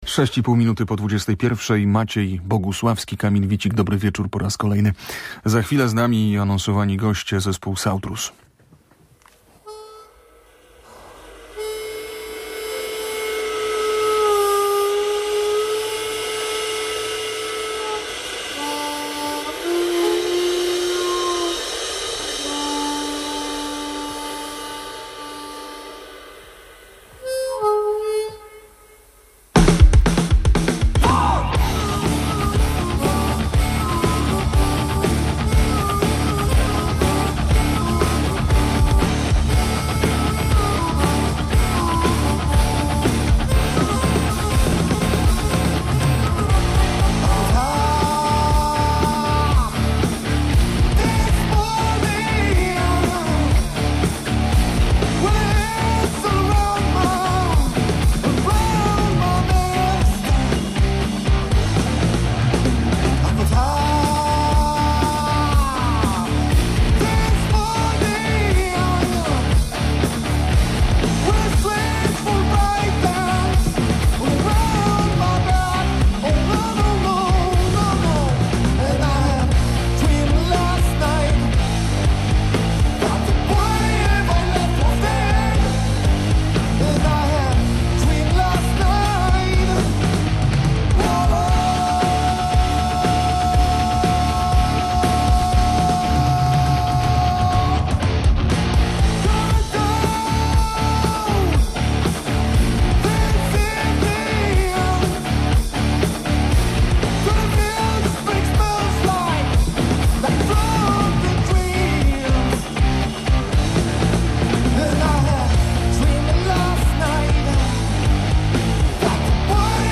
Muzycy zespołu Sautrus o najnowszej płycie w Radiu Gdańsk - Radio Gdańsk